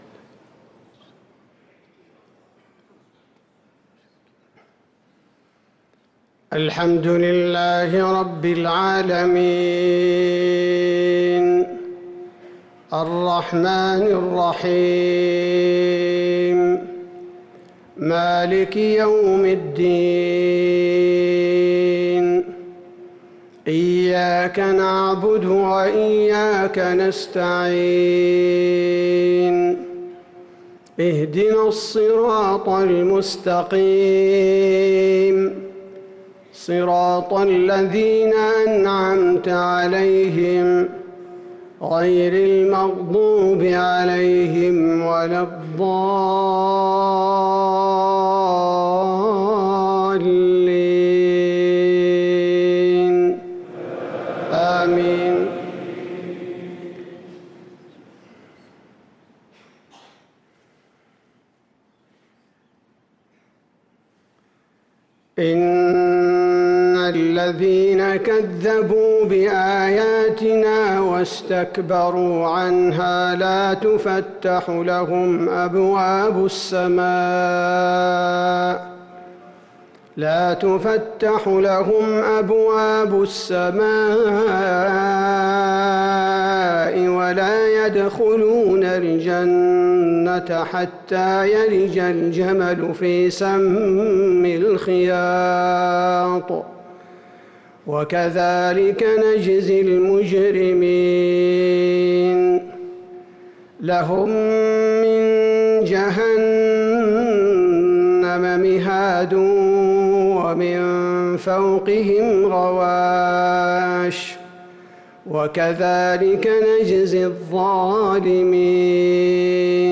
صلاة المغرب للقارئ عبدالباري الثبيتي 18 ربيع الآخر 1445 هـ
تِلَاوَات الْحَرَمَيْن .